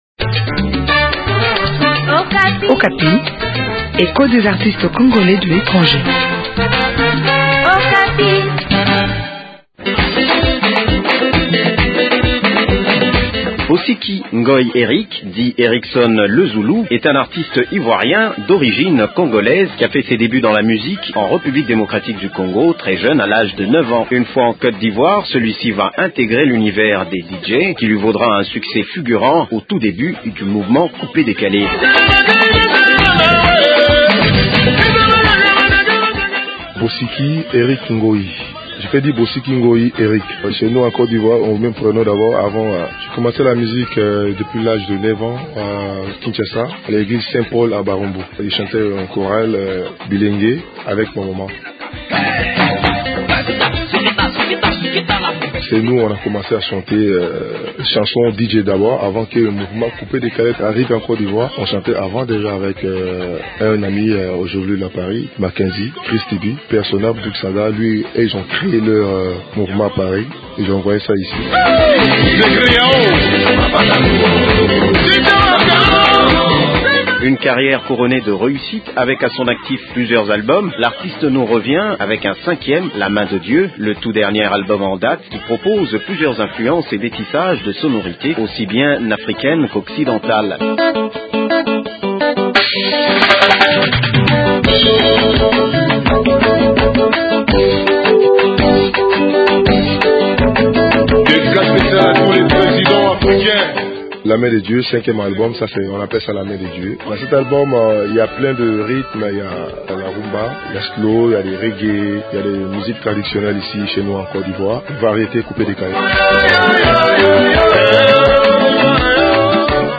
l’artiste parle de sa musique et son Congo natal.